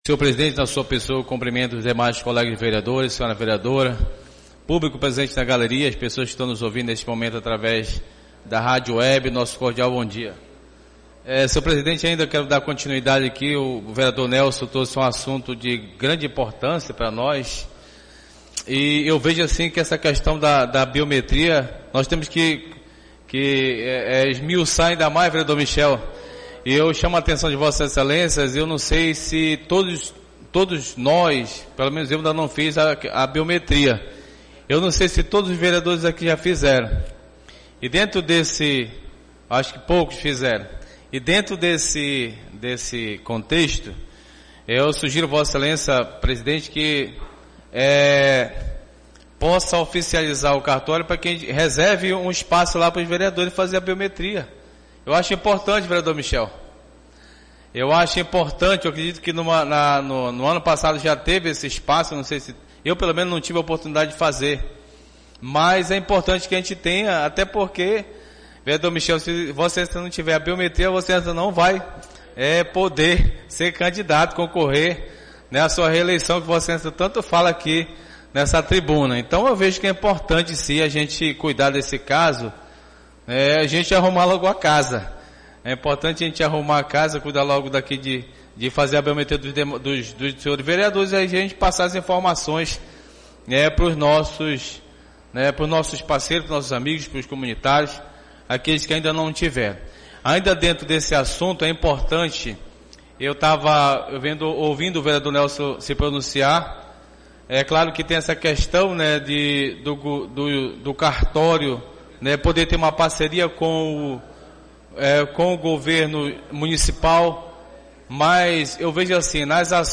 Oradores do Expediente (7ª Sessão Ordinária da 3ª Sessão Legislativa da 31ª Legislatura)